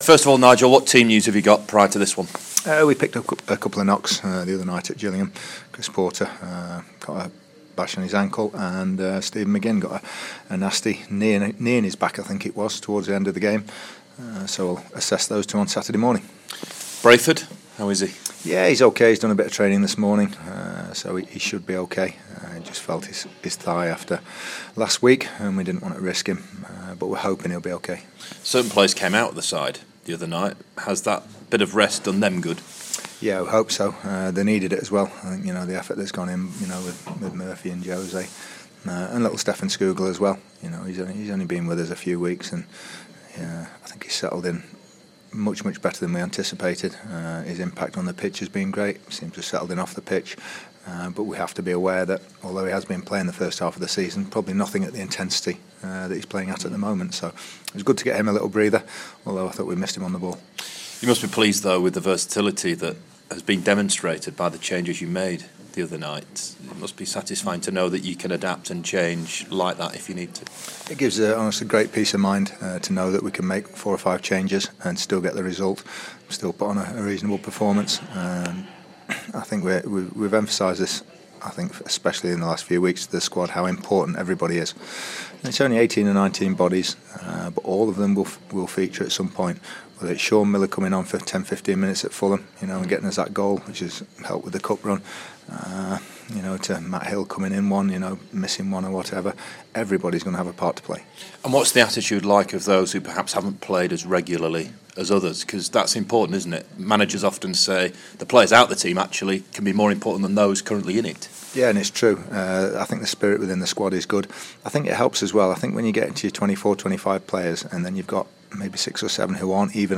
Sheff Utd boss Nigel Clough ahead of Bristol City clash